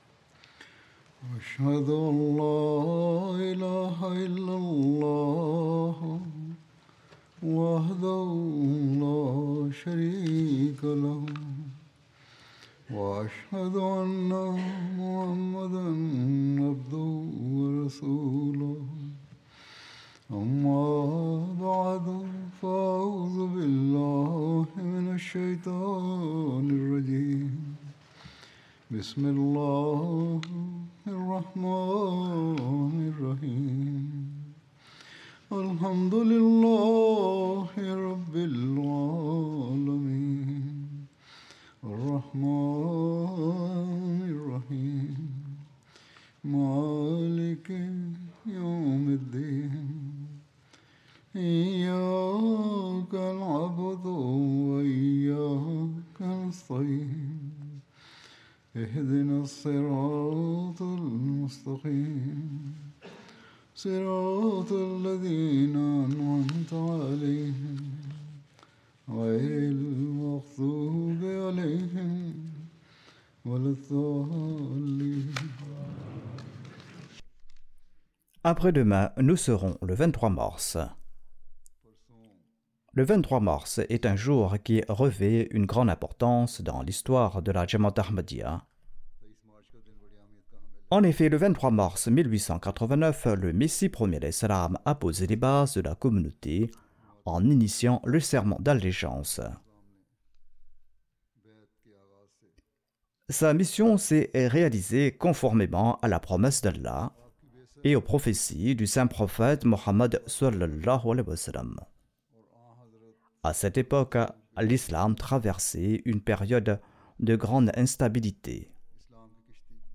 French Friday Sermon by Head of Ahmadiyya Muslim Community
French Translation of Friday Sermon delivered by Khalifatul Masih